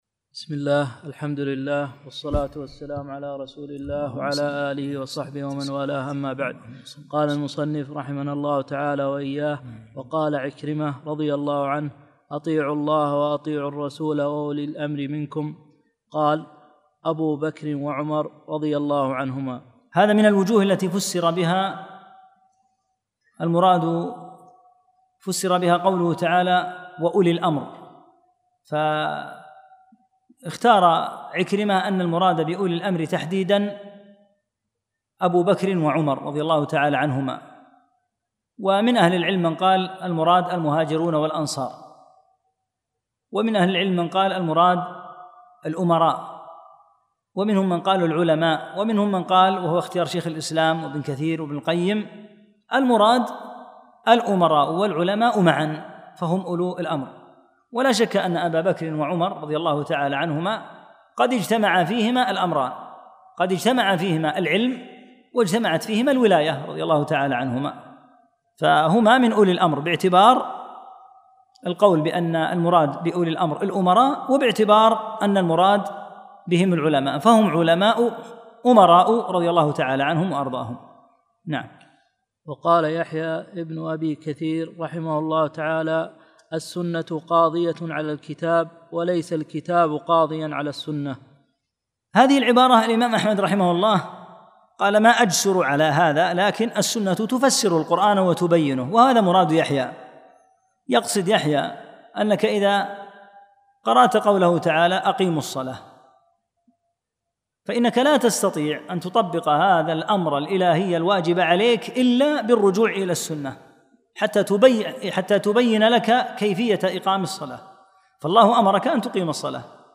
10 - الدرس العاشر